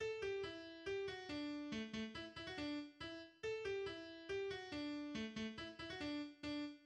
また、終楽章の冒頭で奏でられるドローンの特徴から、古くは『バグパイプ付き』といった意味の "mit dem Dudelsack" という愛称で呼ばれることもあった。
ニ長調、2分の2拍子（アラ・ブレーヴェ）、ソナタ形式。
元気の良い快速なソナタ形式である。